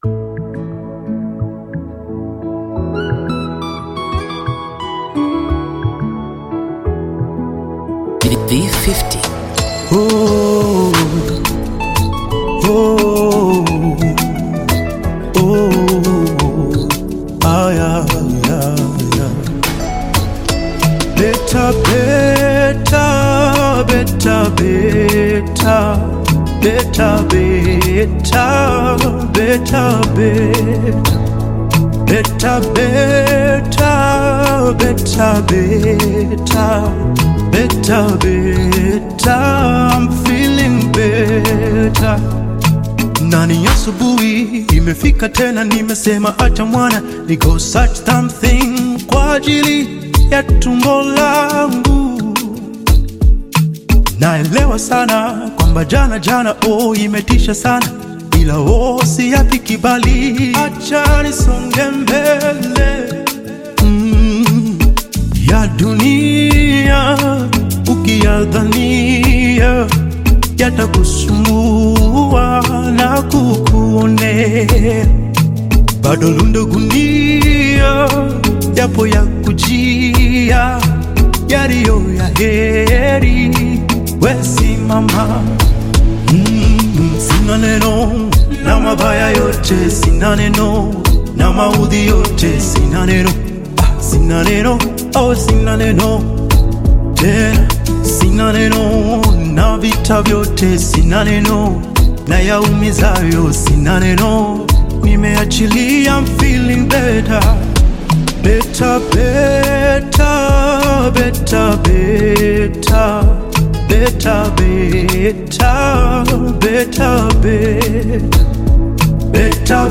Gospel music track